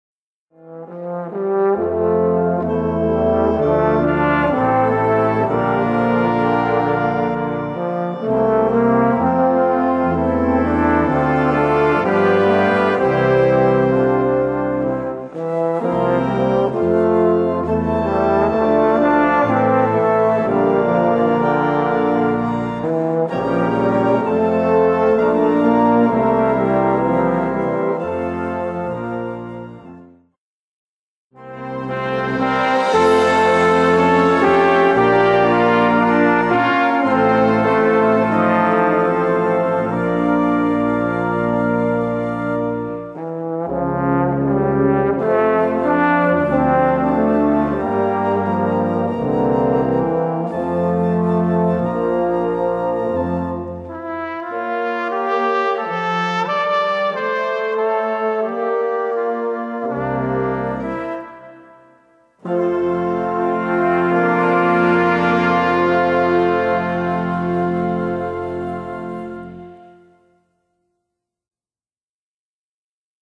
Gattung: Irische Volksweise
Besetzung: Blasorchester
in einem Solo-Arrangement für Posaune / Bariton in Bb/C